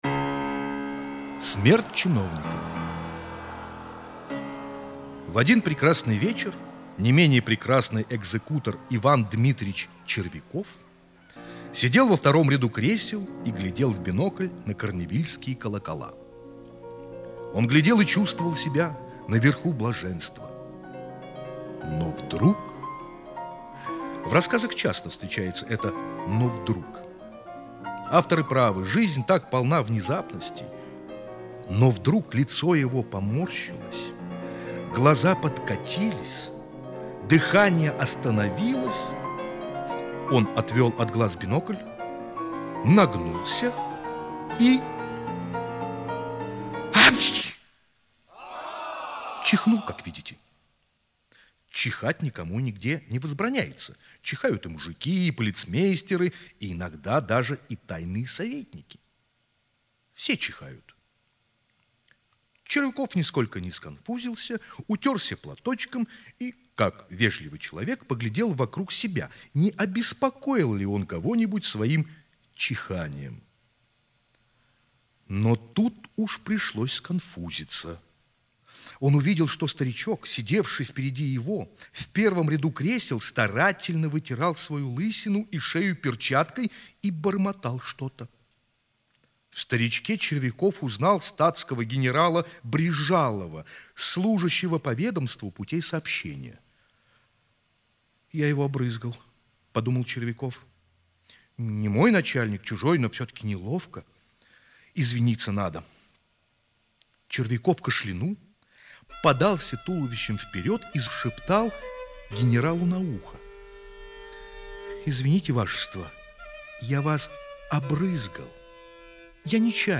Смерть чиновника - аудио рассказ Чехова А.П. Рассказ о том, как чрезмерное раболепие привело робкого мелкого чиновника к смерти.